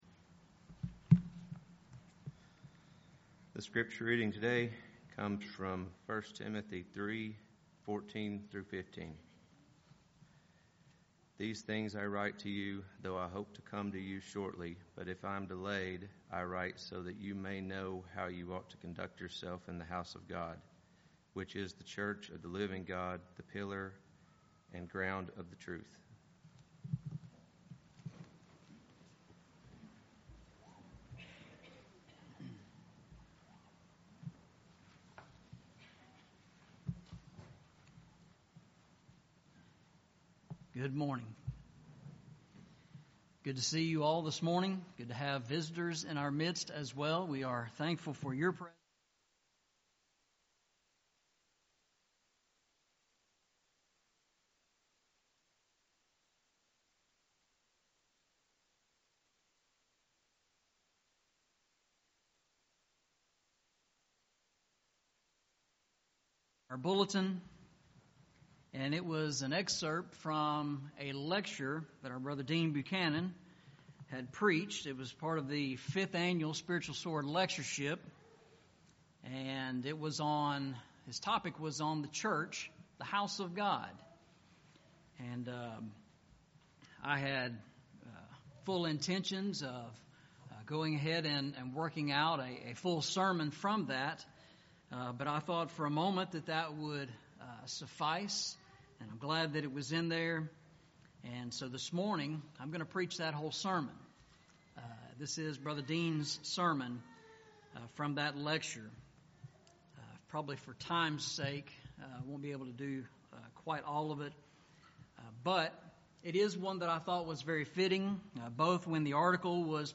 Series: Eastside Sermons Passage: 1 Timothy 3:14-15 Service Type: Sunday Morning « Walking Through the Bible